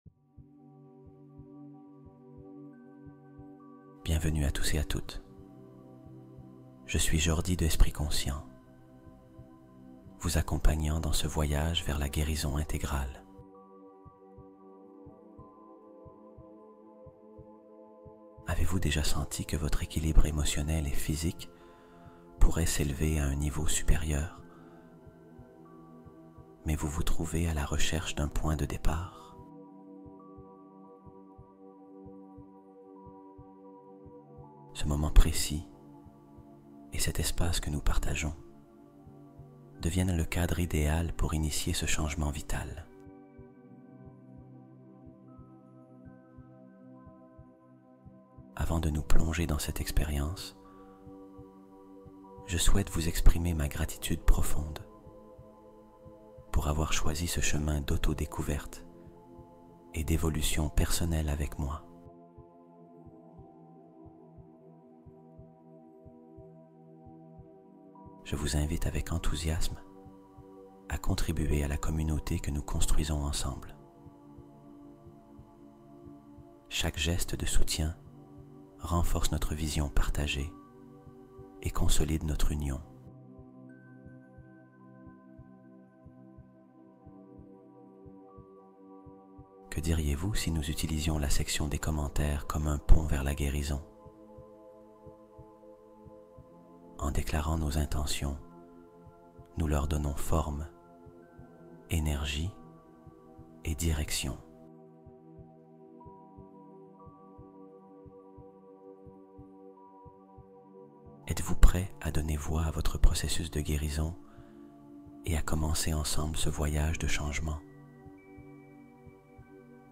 888 Hz : fréquence de libération des blocages et d’expansion intérieure